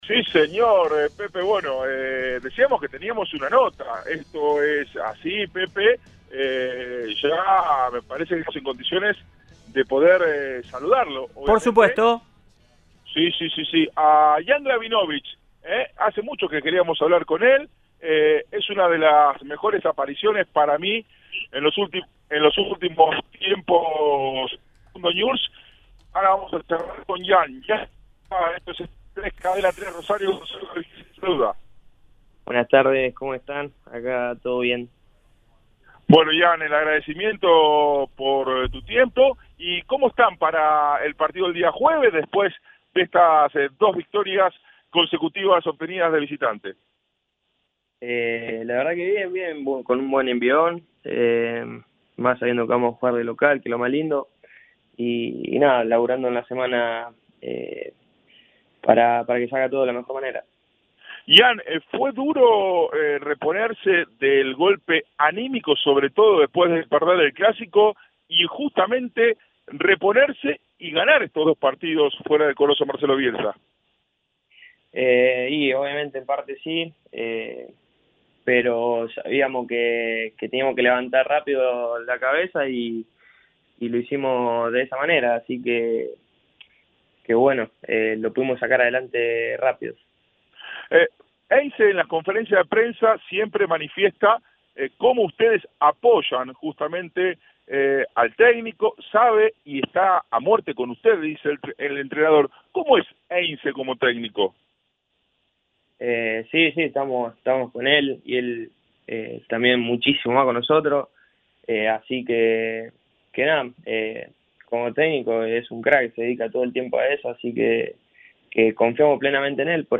El juvenil defensor “leproso” habló en Estadio 3 sobre su buen presente futbolístico, cada vez más asentado en la primera de Newell’s. Su relación con Heinze, sus charlas con Facundo Quiroga y más.